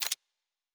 pgs/Assets/Audio/Sci-Fi Sounds/Weapons/Weapon 04 Foley 1.wav at 7452e70b8c5ad2f7daae623e1a952eb18c9caab4
Weapon 04 Foley 1.wav